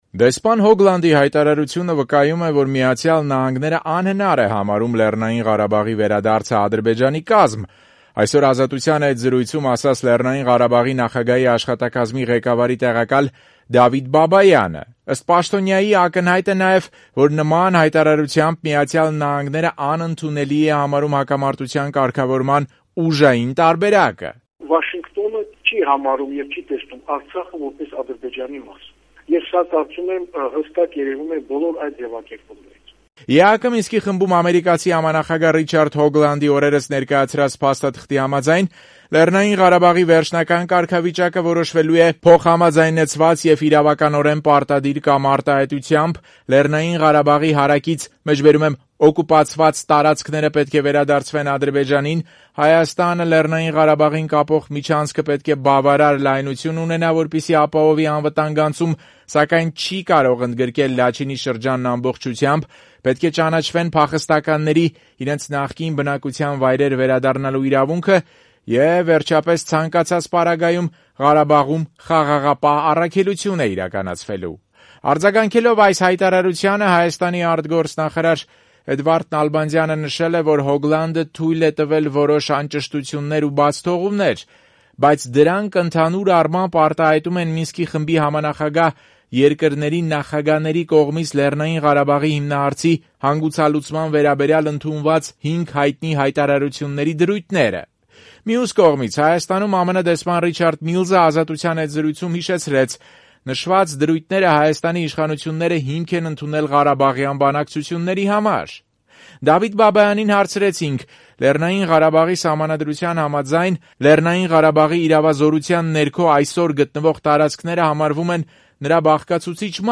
Դեսպան Հոգլանդի հայտարարությունը վկայում է, որ Միացյալ Նահանգներն անհնար է համարում Լեռնային Ղարաբաղի վերադարձը Ադրբեջանի կազմ: Այսօր «Ազատության» հետ զրույցում նման դիտարկում արեց Լեռնային Ղարաբաղի նախագահի աշխատակազմի ղեկավարի տեղակալ Դավիթ Բաբայանը: Ըստ նրա, «ակնհայտ է նաև», որ նման...